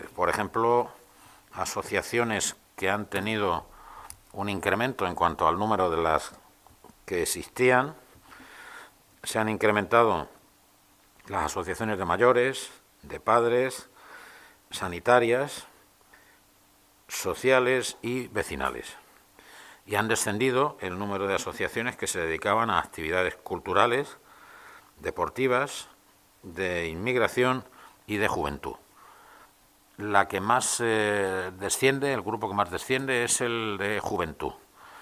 AUDIOS. Juan José Pérez del Pino, concejal de Participación Ciudadana